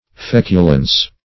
Feculence \Fec"u*lence\, n. [L. faeculentia dregs, filth: cf. F.